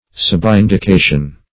Search Result for " subindication" : The Collaborative International Dictionary of English v.0.48: Subindication \Sub*in`di*ca"tion\, n. The act of indicating by signs; a slight indication.